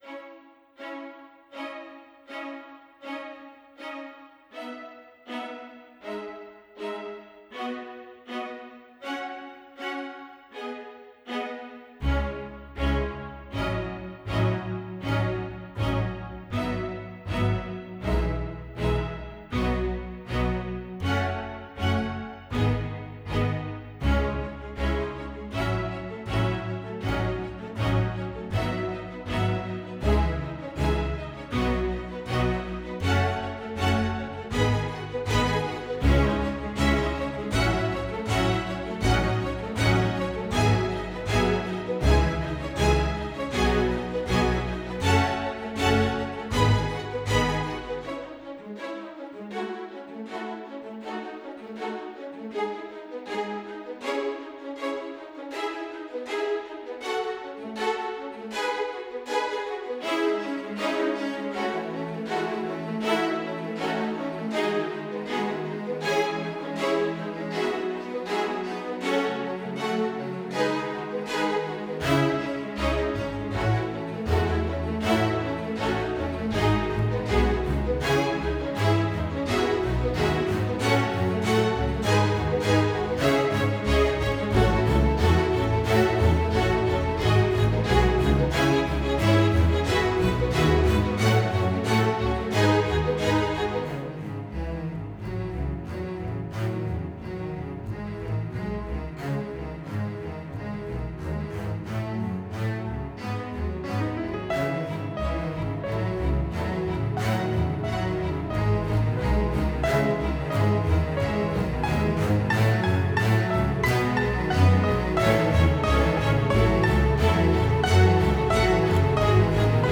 quatre orchestrations minimalistes